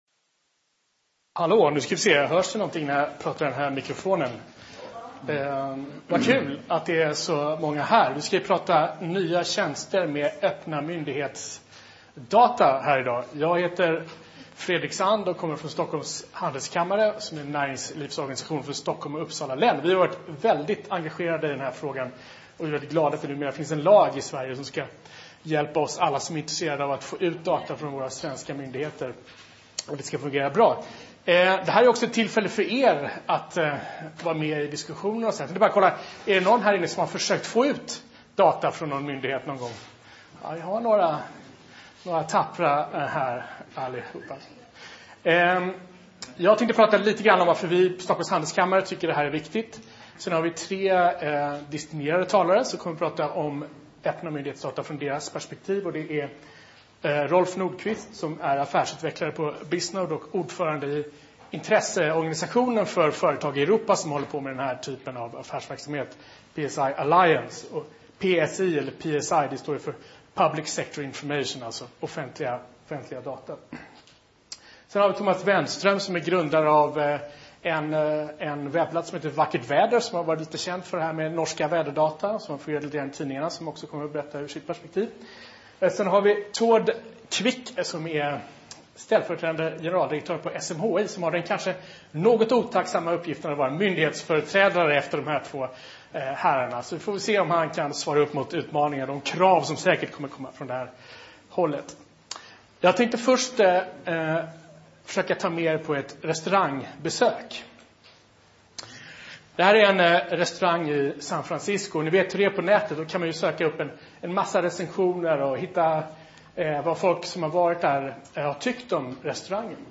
Seminariet är för dig som vill lära dig mer om möjligheter och hinder för denna utveckling.